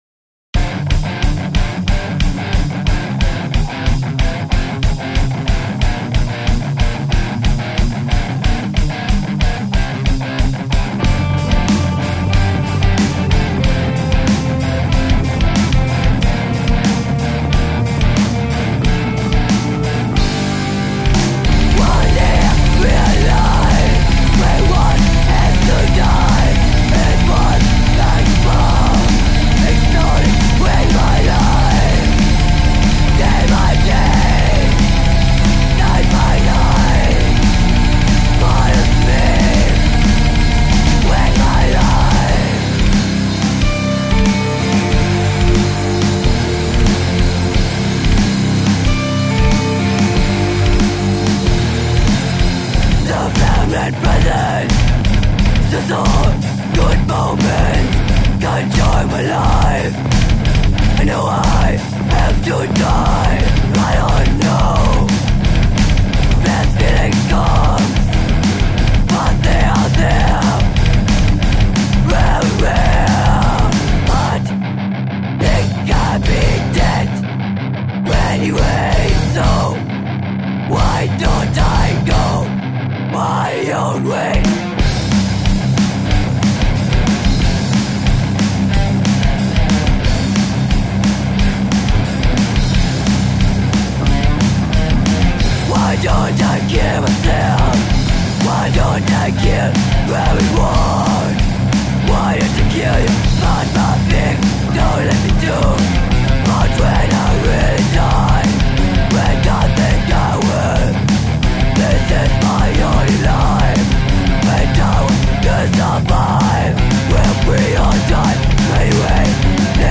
Mischung aus Metal und Hardcore mit Frontfrau
Guitar
Bass
Drums